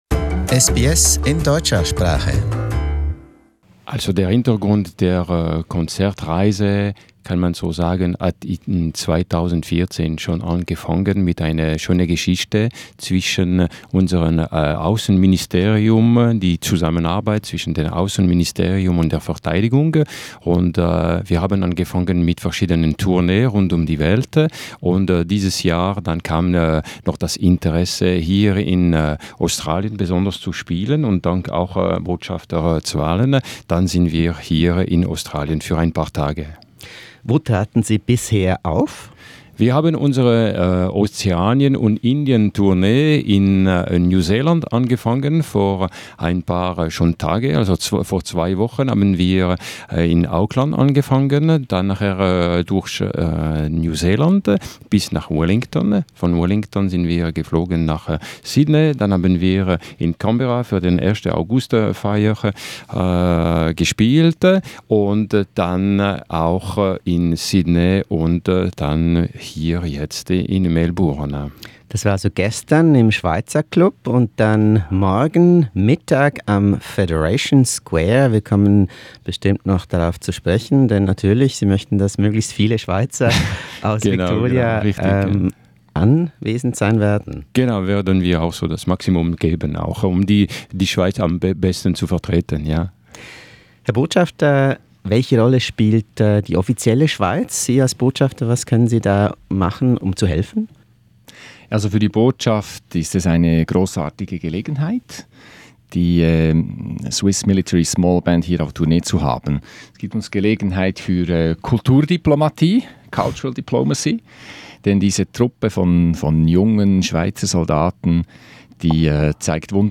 And what is the purpose of a military band? To find out, listen to the interview, with music performed by the Swiss Military Small Band.